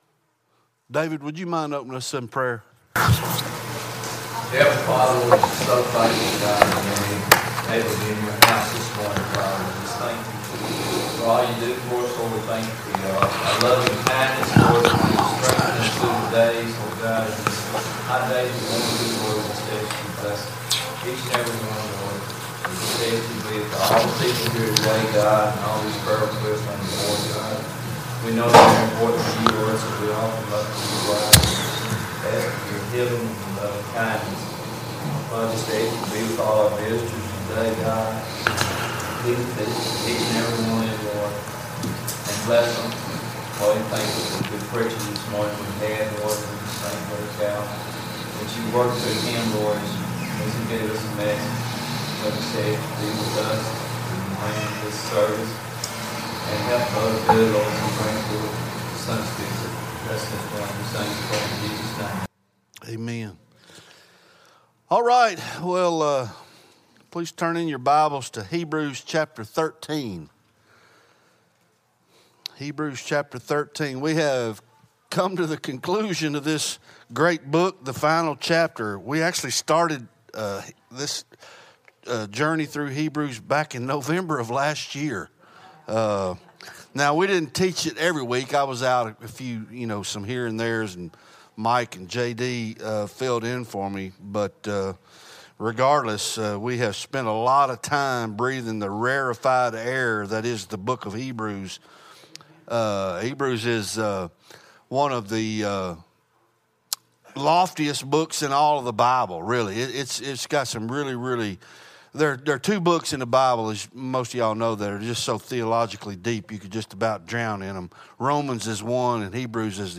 Bible Study Hebrews Ch 13